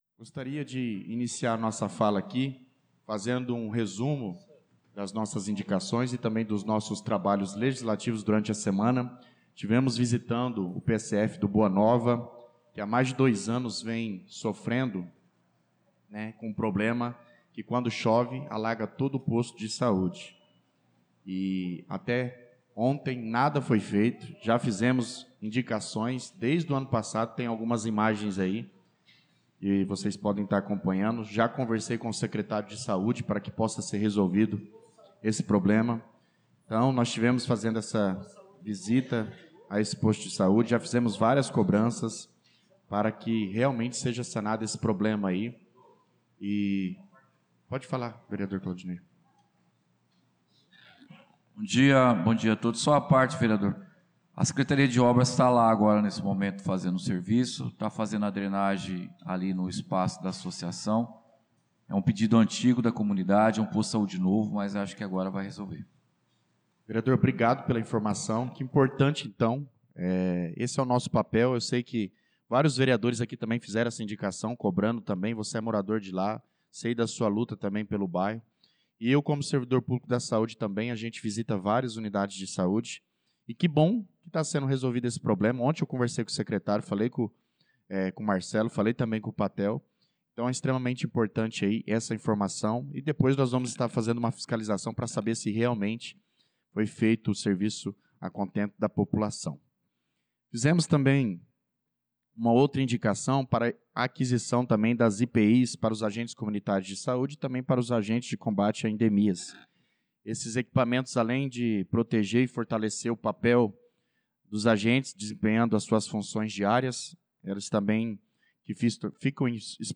Pronunciamento do vereador Douglas Teixeira na Sessão Ordinária do dia 25/03/2025